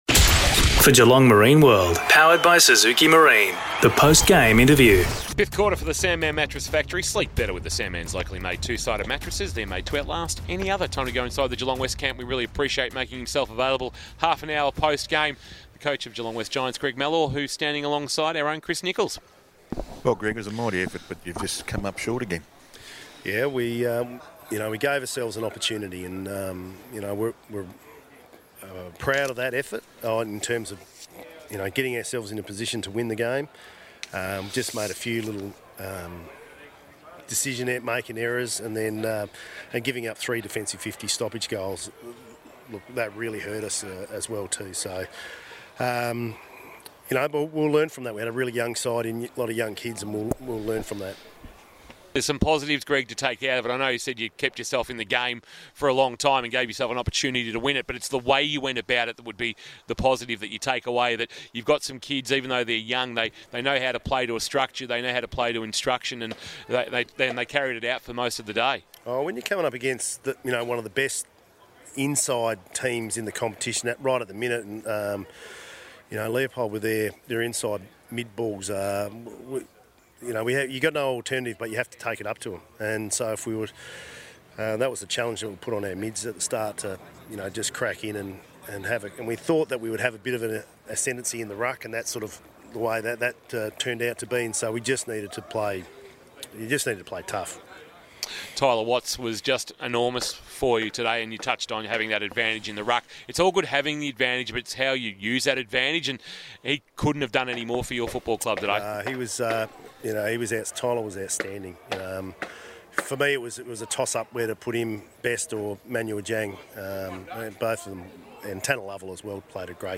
2022 – GFL ROUND 12 – GEELONG WEST vs. LEOPOLD: Post-match Interview